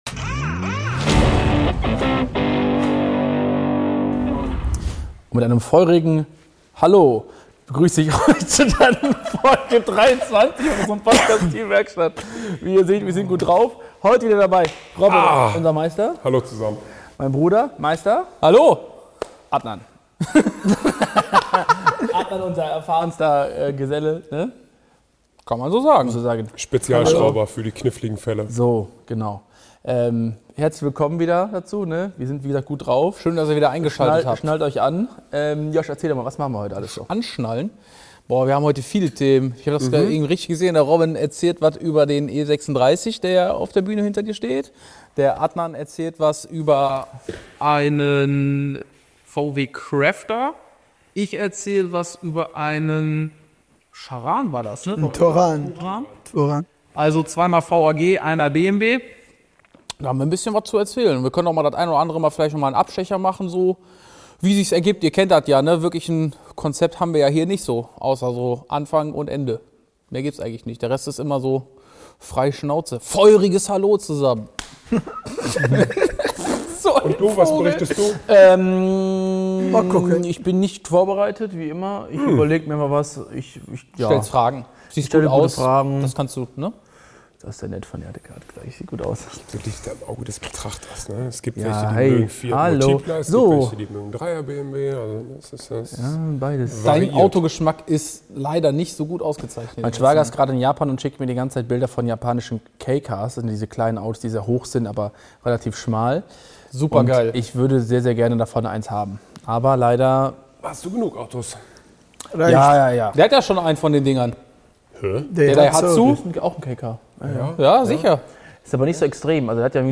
#23 ZÜNDSTOFF ~ TEAM WERKSTATT | Der Feierabend-Talk aus der Werkstatt der Autodoktoren Podcast